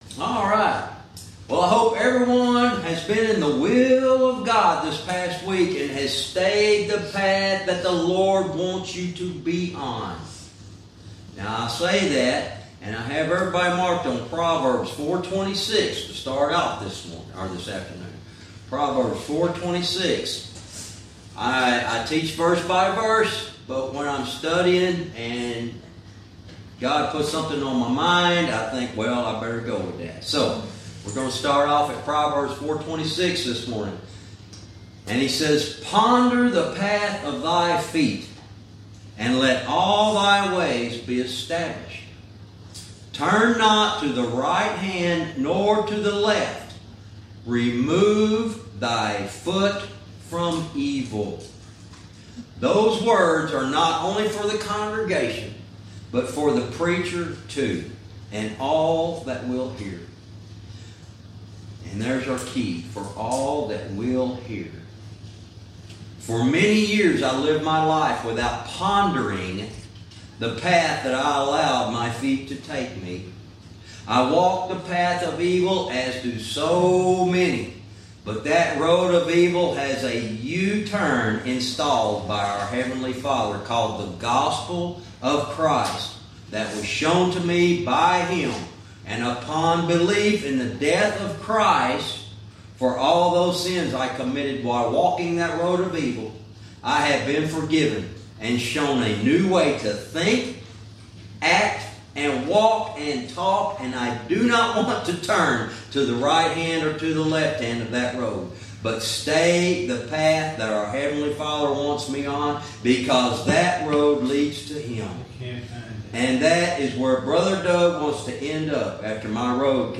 Verse by verse teaching - Jude lesson 74 verse 17